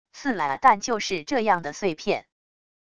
刺啦~~~但就是这样的碎片wav音频